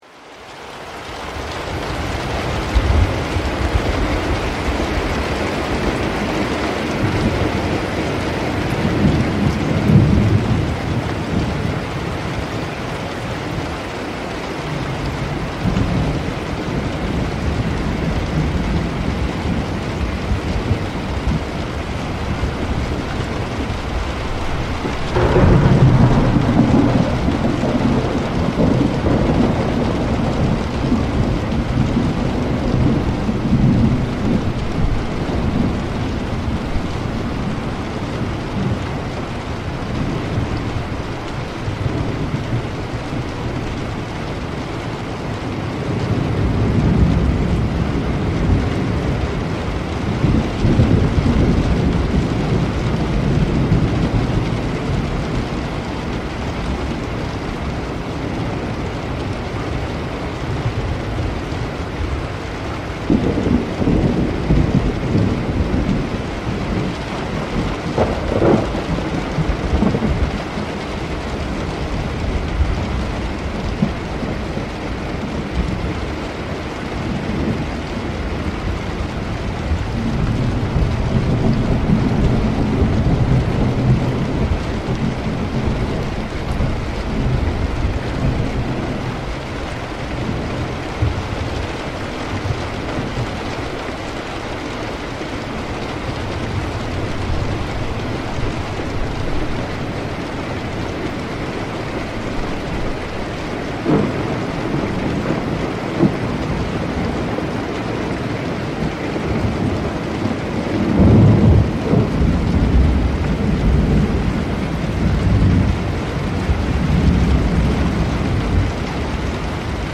orage avec de fortes pluies pour dormir etudier et se detendre the hideout ambience.mp3